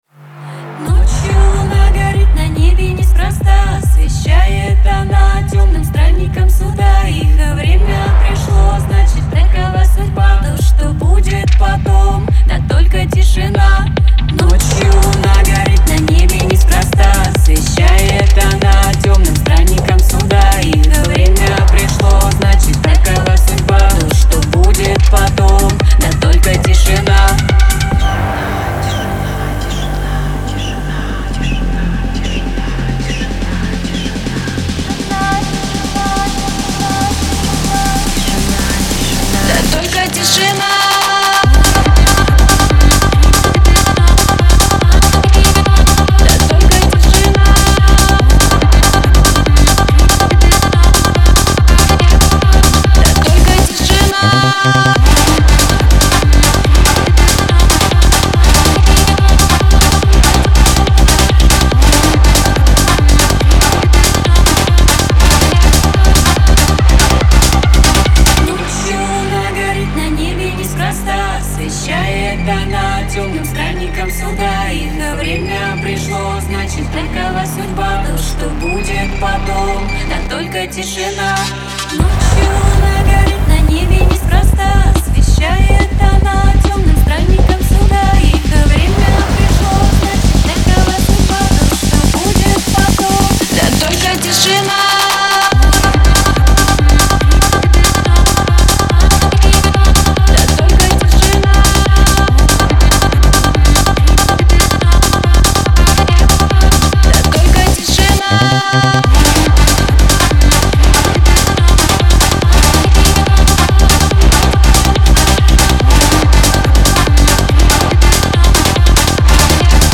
pop
Веселая музыка